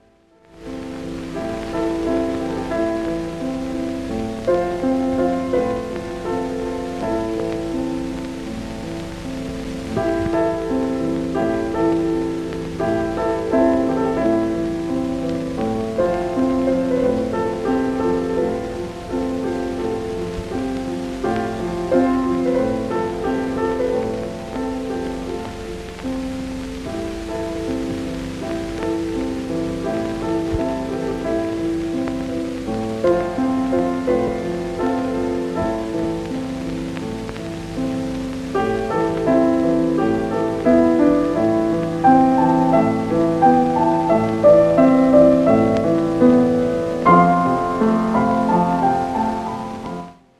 Its impressionist piano interlude is unforgettable.
excerpts are from original film soundtrack.